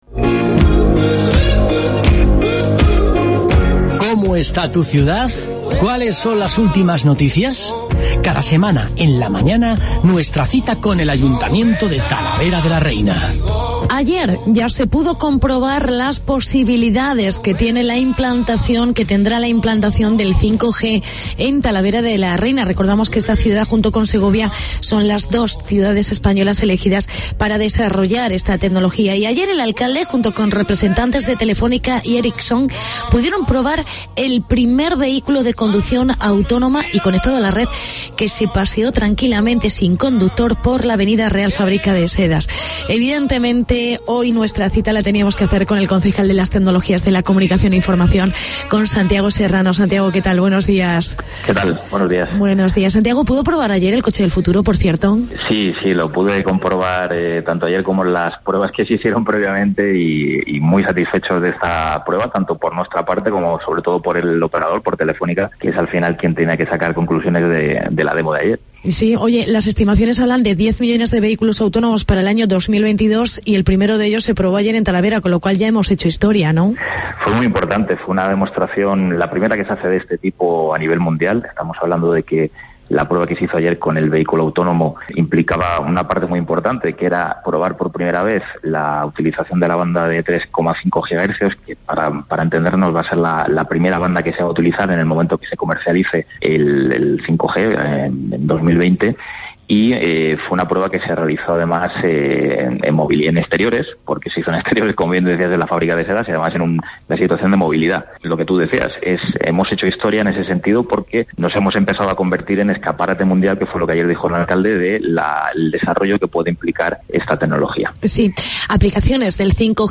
Entrevista al concejal Santiago Serrano